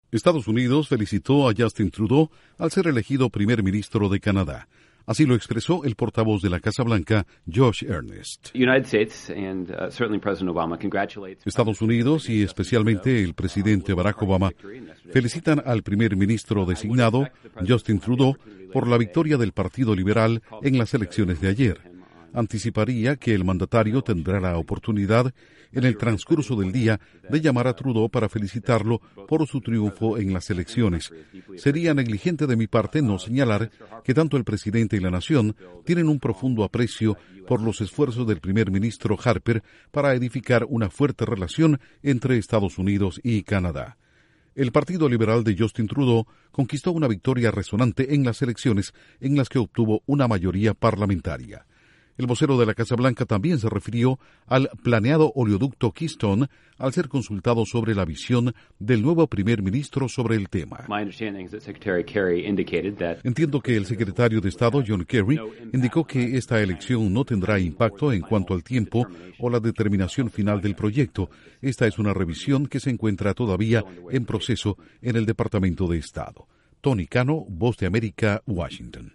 Estados Unidos felicita a Trudeau por su victoria como nuevo Primer Ministro de Canadá, y descarta que su elección tendrá algún impacto en el futuro del oleoducto Keystone. Informa desde la Voz de América en Washington